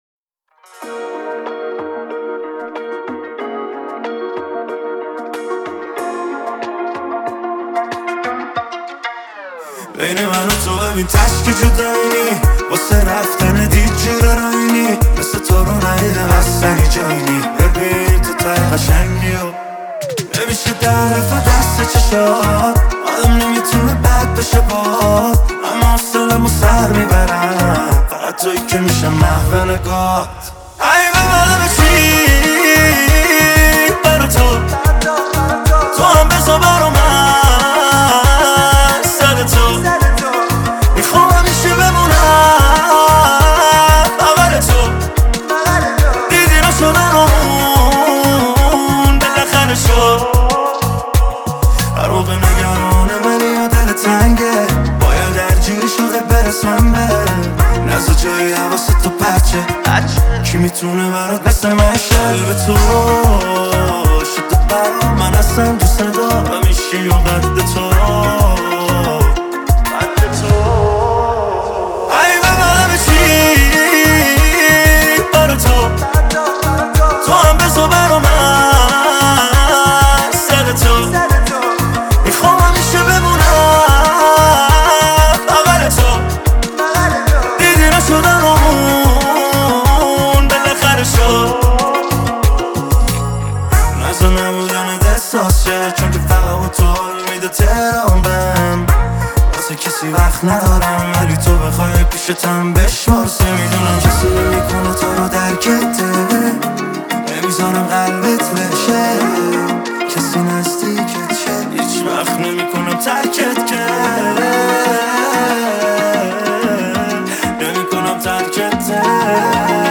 عاشقانه